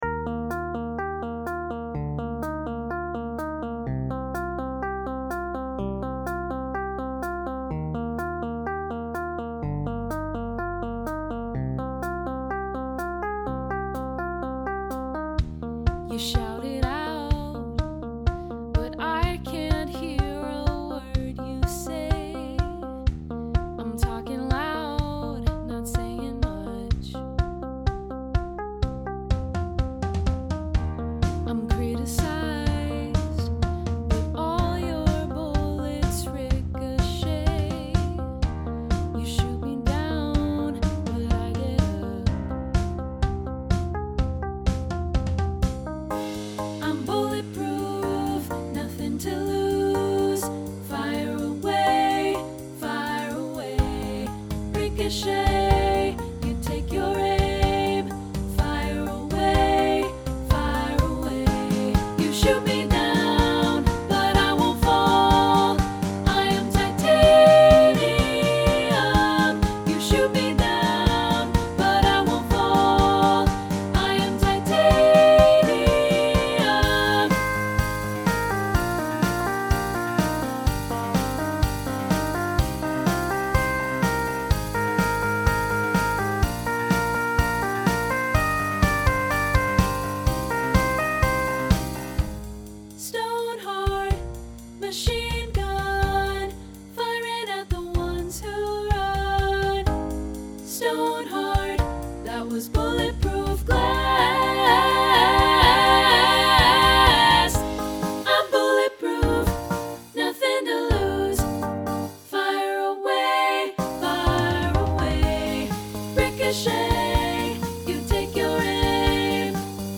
contemporary choral SSA arrangement
Check out the studio demo (MIDI instruments + live voices):
Instrumentation: Piano, Guitar, Bass, Drumset
SSAA Pop Choral